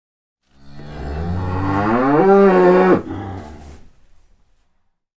دانلود صدای گاو نر خشمگین با نعره بلند از ساعد نیوز با لینک مستقیم و کیفیت بالا
جلوه های صوتی